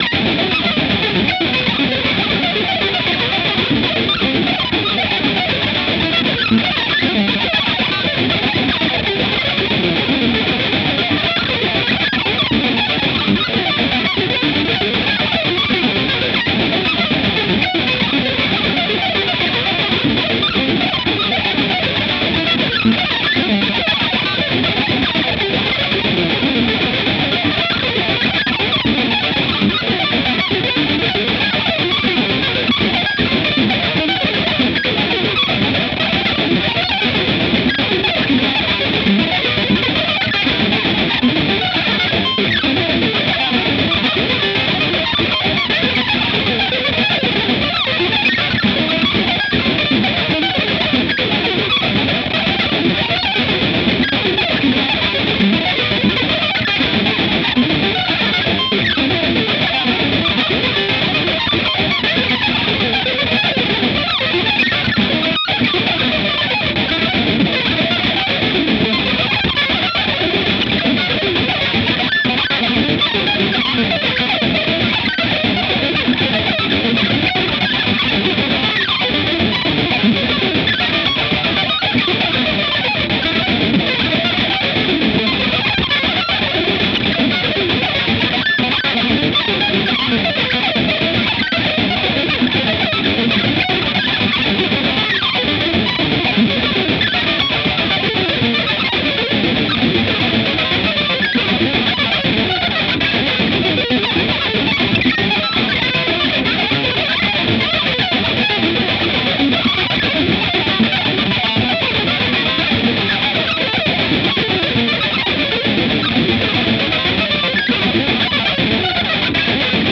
タイトル通り、切り刻み続けるような演奏表現に満たされたアルバムです。
ケチャ、ジェゴグ、ガムランなどにみられるような、原始の祝祭音楽の酩酊感がそこにあります。
それぞれ異なるBPMの小曲（各4分間）が12曲収録されています。
ギター愛好家の方々にはもちろん、現代音楽、先端的テクノ、実験音楽をお好きな方々にもお薦めのアルバムです。